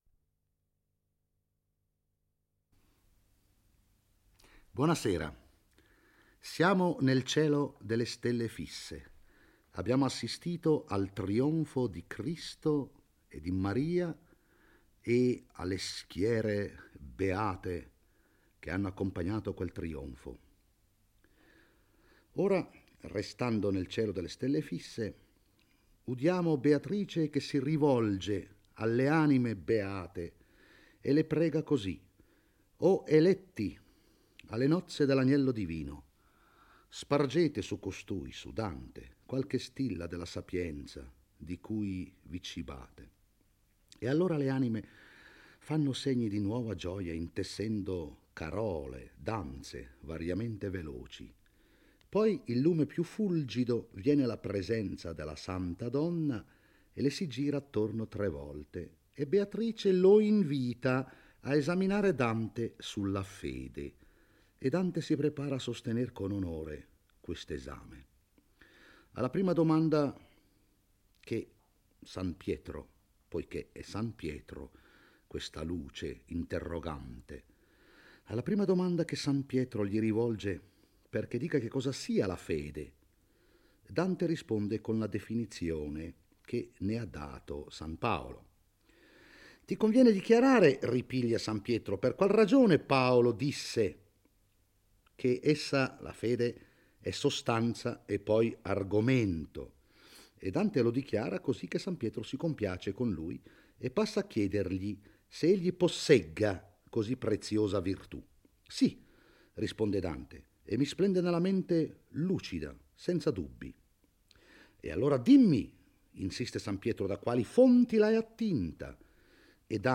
legge e commenta il XXIV canto del Paradiso.